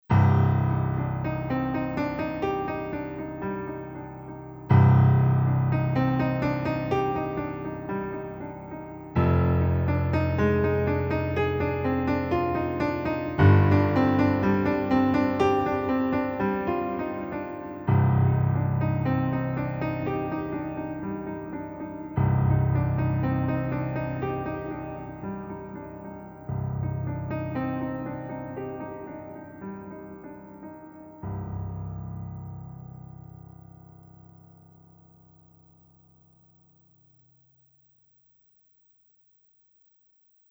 is a piano tolling the ominous and inevitable message